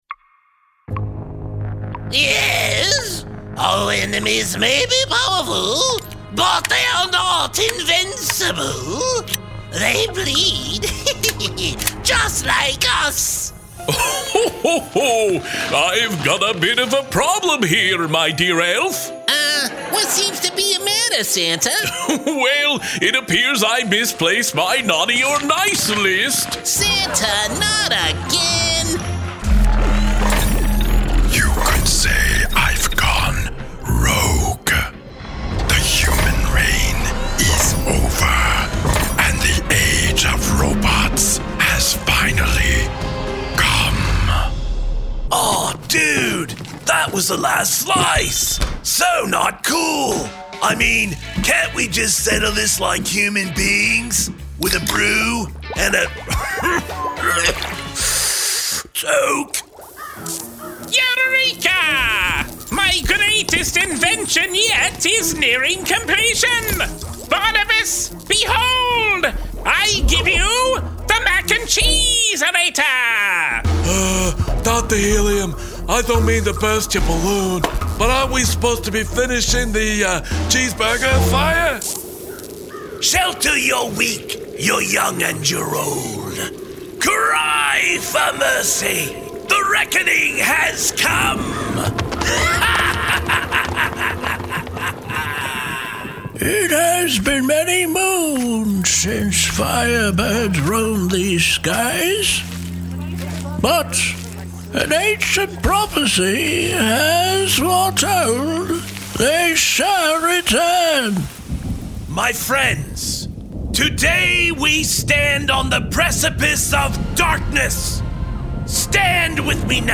Adult (30-50) | Older Sound (50+)
Our voice over talent record in their professional studios, so you save money!